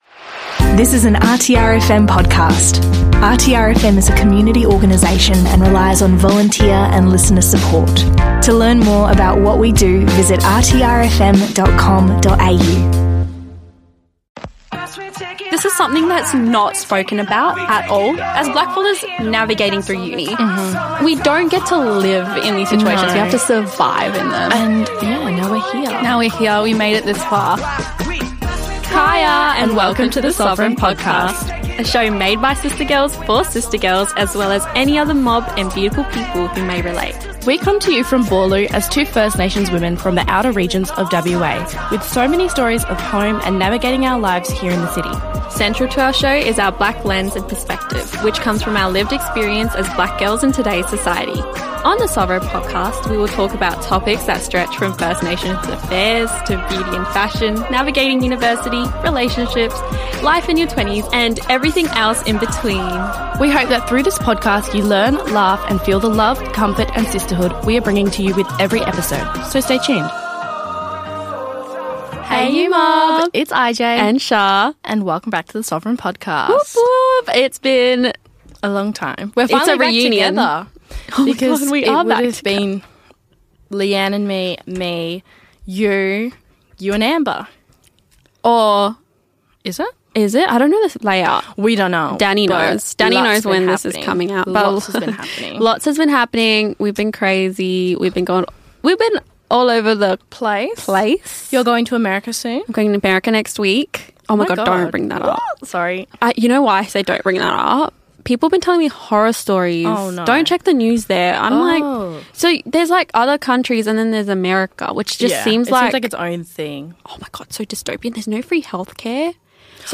After too much time apart the girls are back in the big red studio to…well…yap.